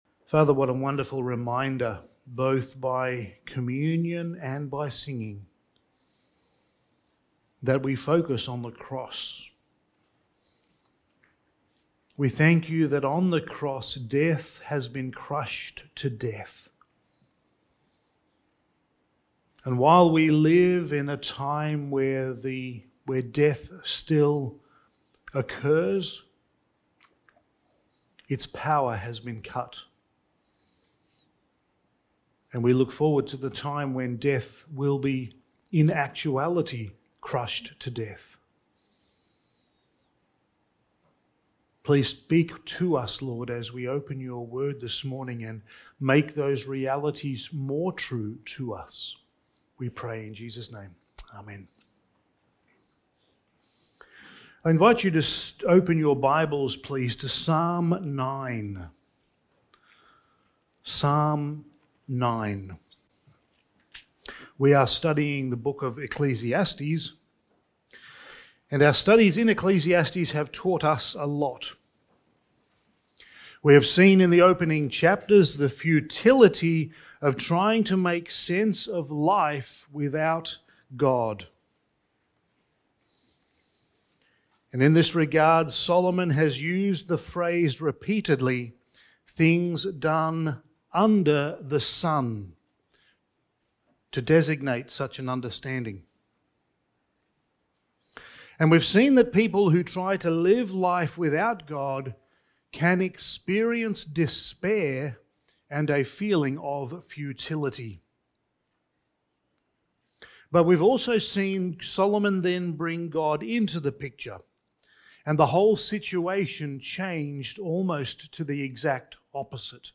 Studies in the Book of Ecclesiastes Sermon 7: Death, Dust and Glory
Service Type: Sunday Morning